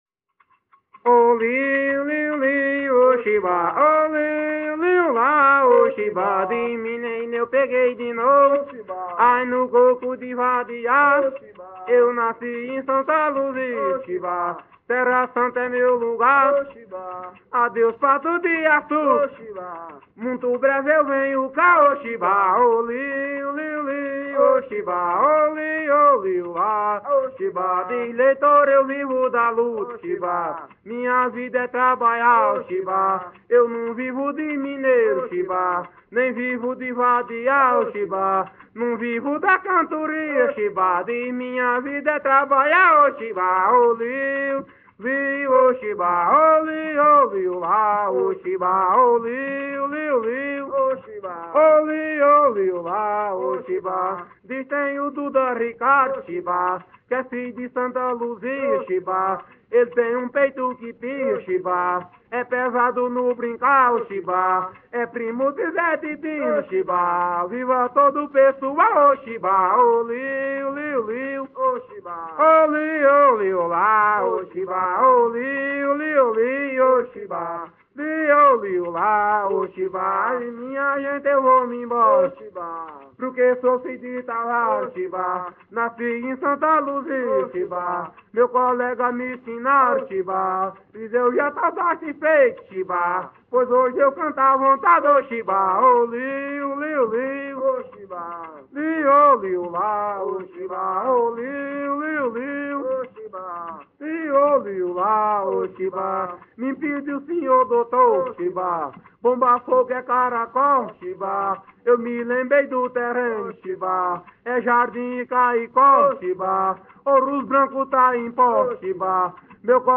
Coco parcelado- “”Oh chiba”” - Acervos - Centro Cultural São Paulo